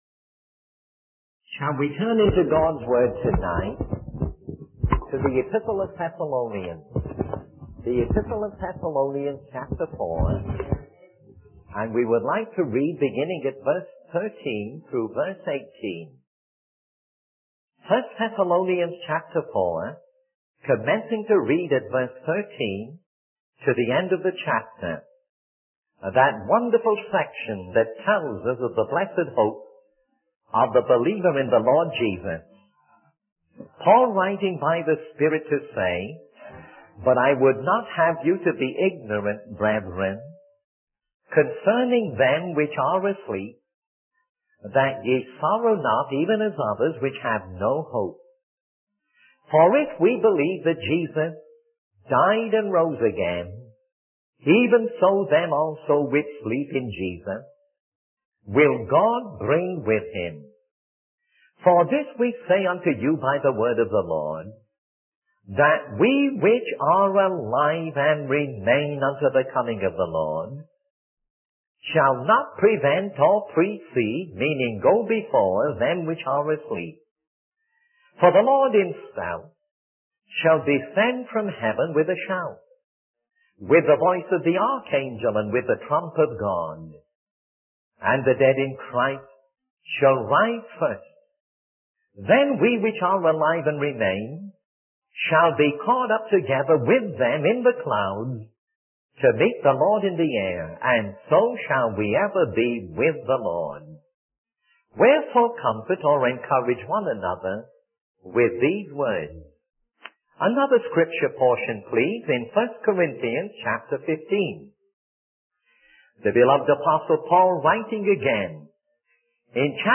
In this sermon, the preacher discusses the coming event of the Lord's return for his church. He emphasizes the significance of the developments and discoveries in the field of air travel and communication, suggesting that they foreshadow the coming of the Lord. The preacher urges the audience to acknowledge their sinful nature and the need for Jesus Christ as the only savior.